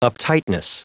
Prononciation du mot uptightness en anglais (fichier audio)
Prononciation du mot : uptightness